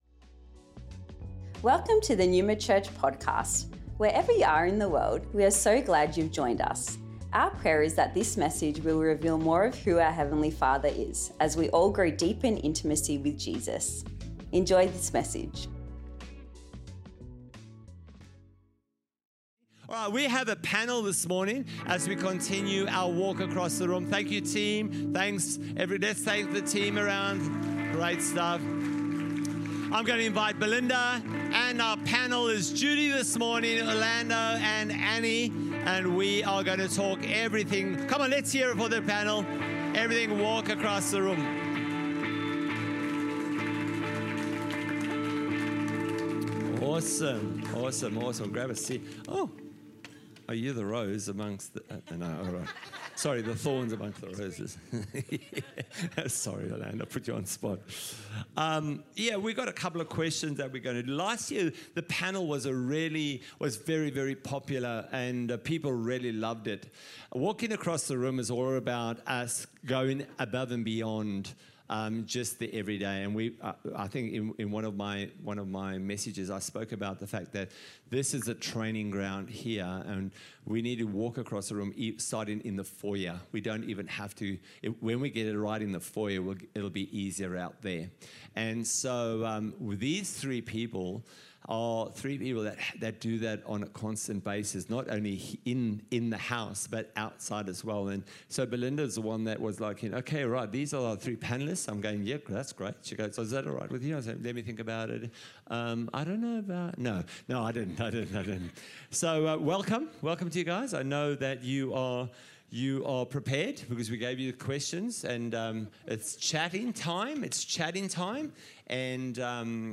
Walk Across The Room Panel | Neuma Church Melbourne East